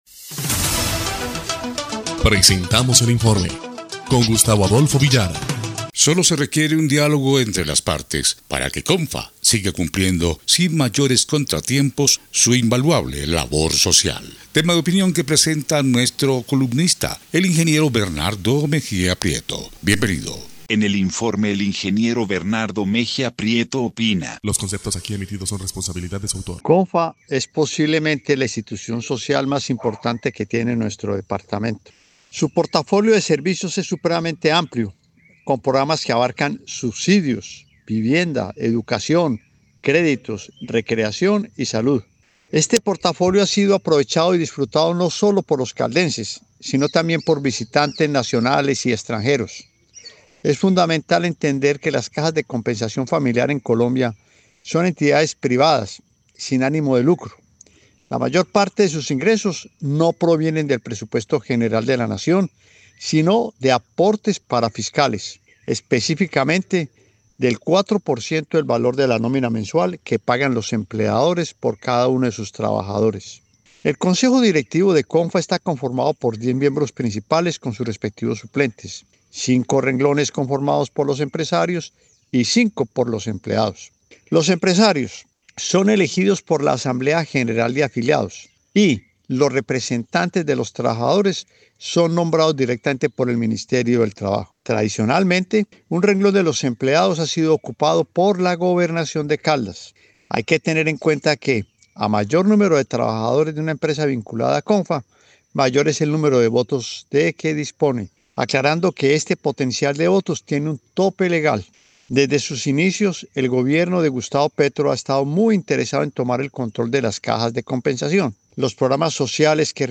EL INFORME 1° Clip de Noticias del 6 de abril de 2026